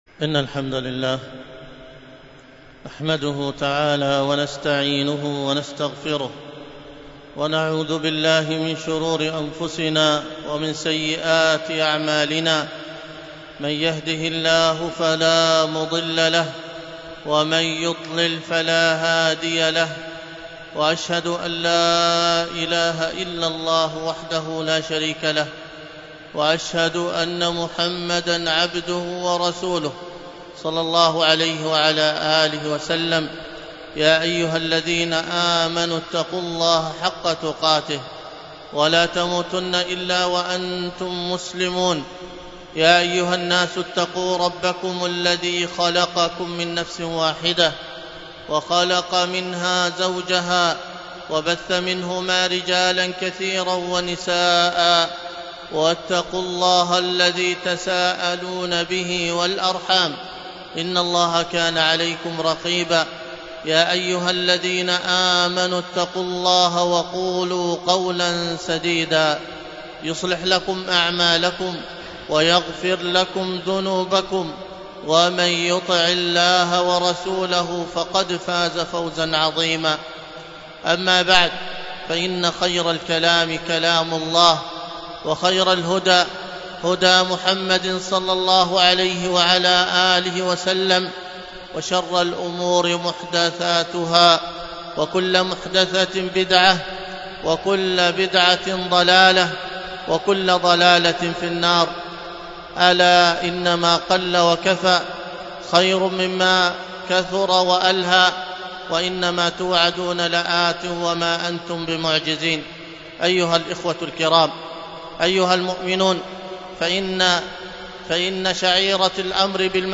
الخطبة